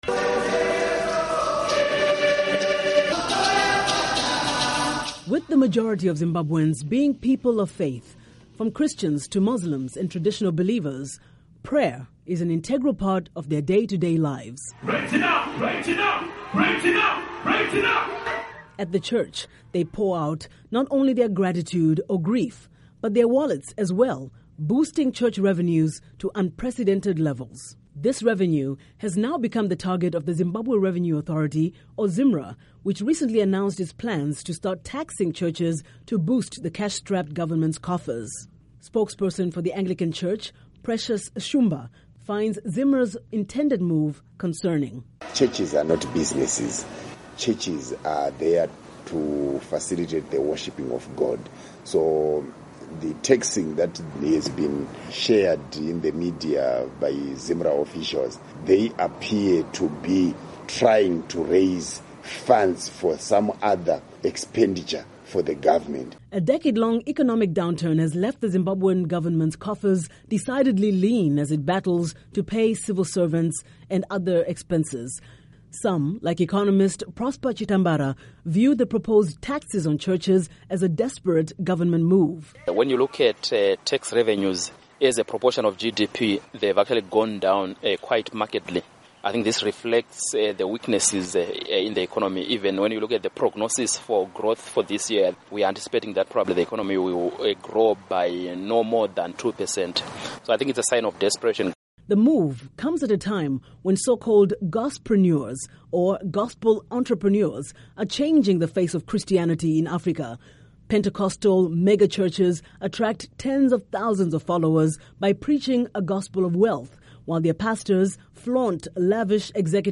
Report on Zimra's Plans To Tax Zimbabwe's Churches